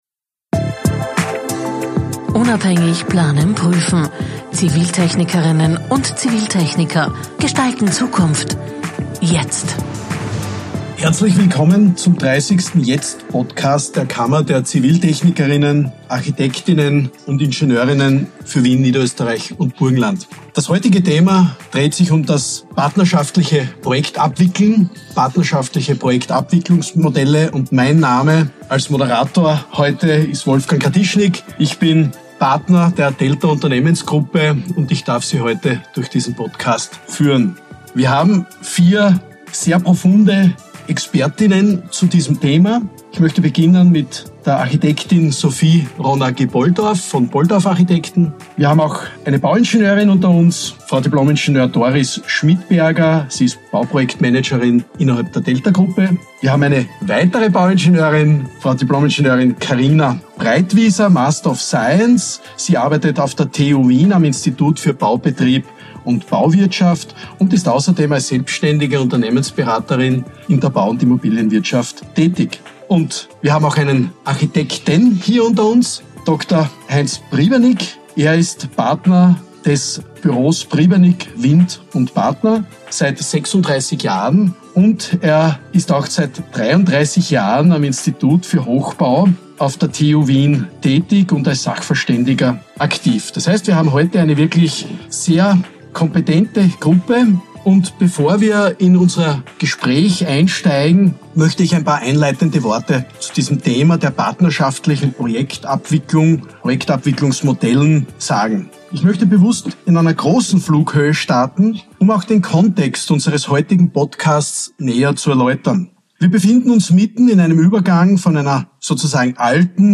Partnerschaftliche Projektabwicklung bietet eine zukunftsweisende Antwort. In dieser Episode diskutieren Expert:innen, warum es neue Denkweisen und echte Kooperation braucht, um Bauprojekte effizienter, erfolgreicher und nachhaltiger zu machen – und wie wir durch gemeinsames Handeln die Branche verändern können.